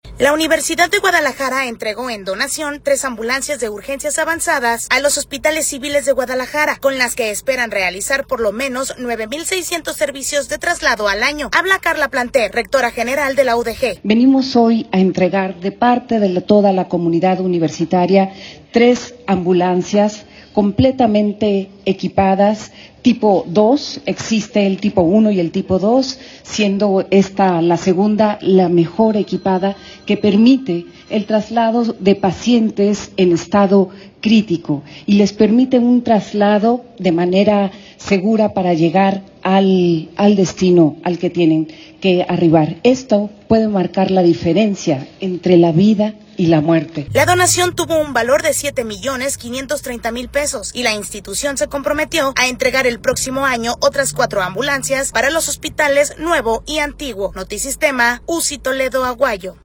La Universidad de Guadalajara entregó en donación tres de ambulancias de urgencias avanzadas a los Hospitales Civiles de Guadalajara con las que esperan realizar por lo menos 9 mil 600 servicios de traslado al año. Habla Karla Planter, rectora general de la U de G.